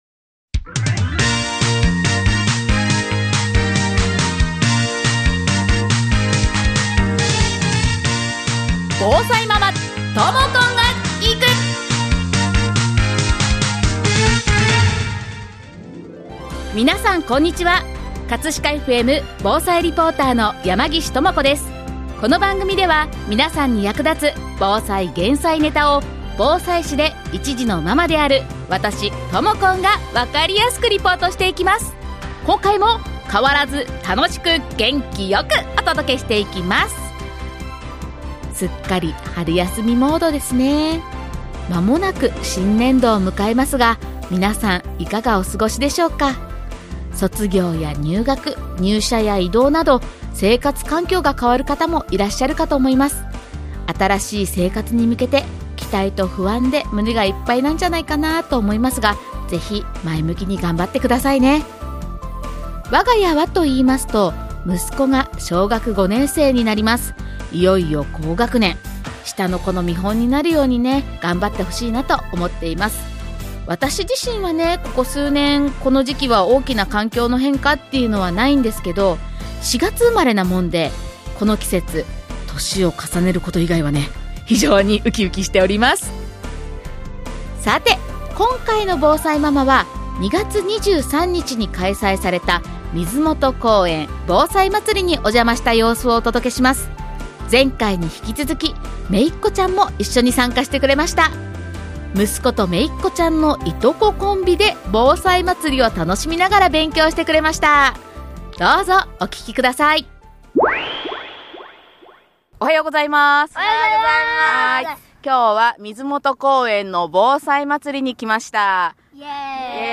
今回は、2月23日(日)に開催された水元公園の防災まつりにお邪魔した様子をお届けします！